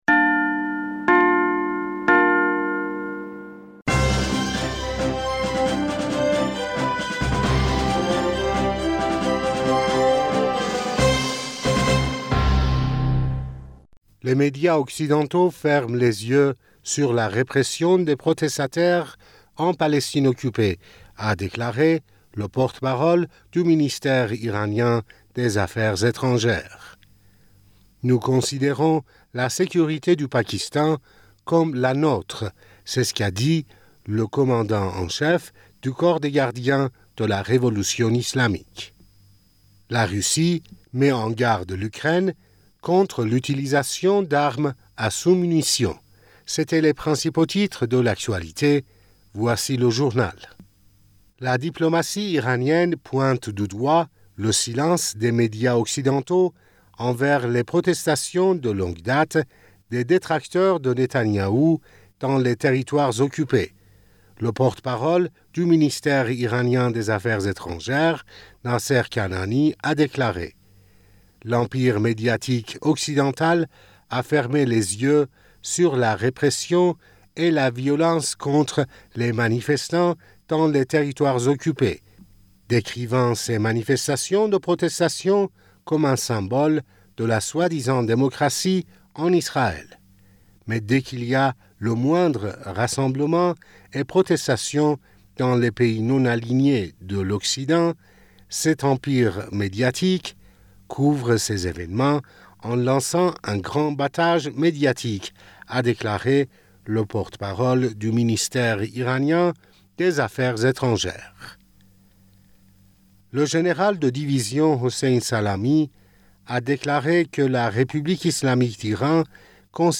Bulletin d'information du 16 Juillet 2023